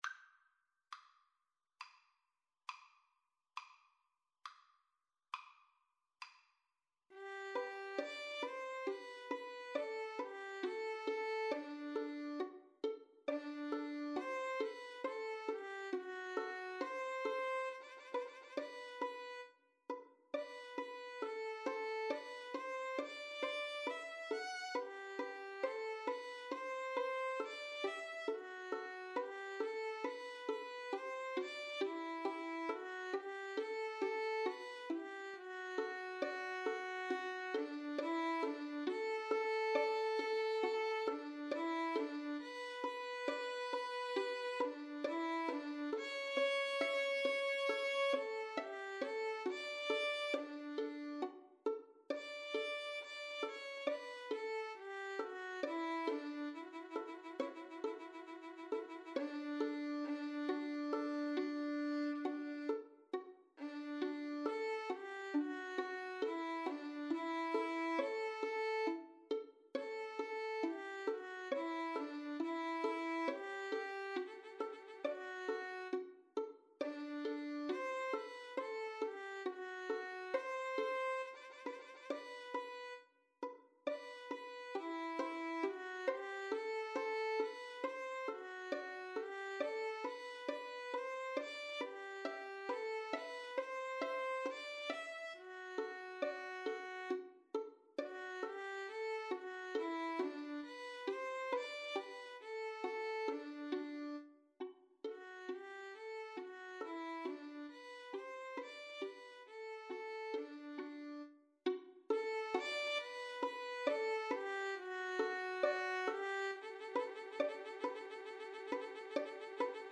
G major (Sounding Pitch) (View more G major Music for 2-Violins-Cello )
= 34 Grave
Classical (View more Classical 2-Violins-Cello Music)